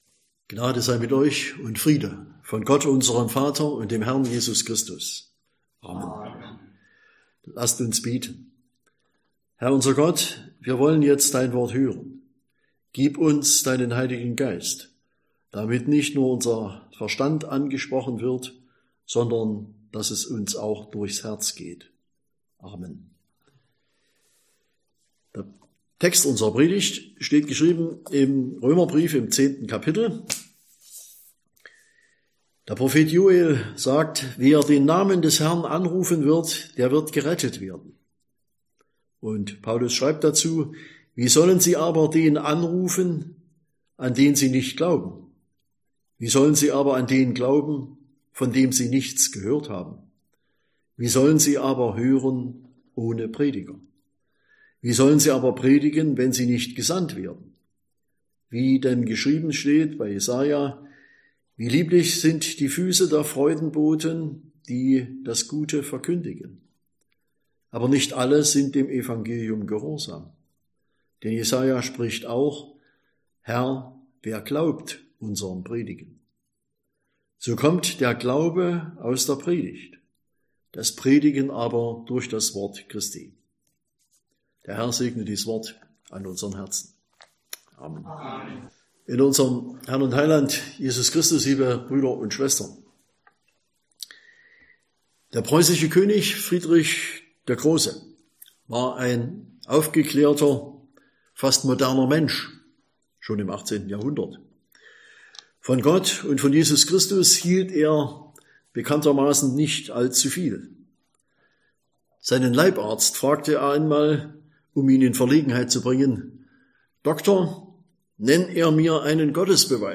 Sonntag nach Trinitatis Passage: Römer 10, 14-17 Verkündigungsart: Predigt « 14.